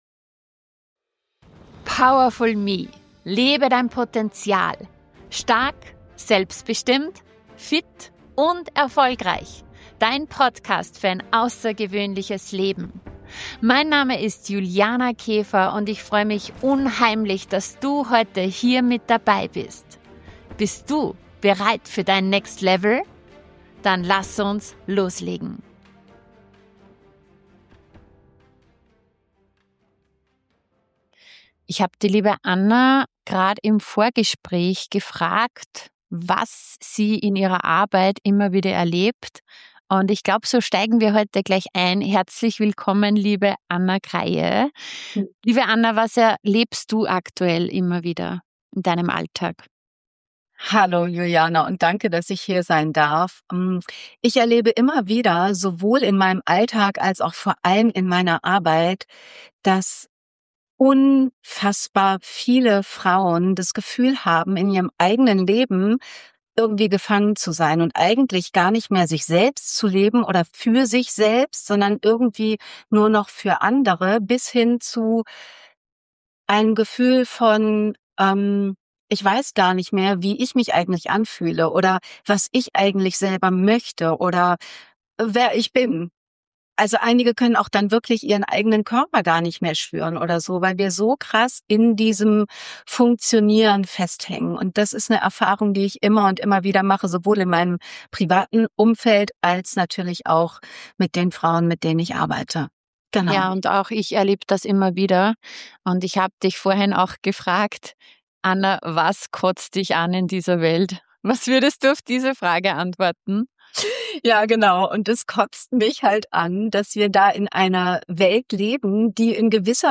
Ein Gespräch über weibliche Kraft, innere Verbindung und die Erlaubnis, dein eigenes Leben wieder wirklich zu leben.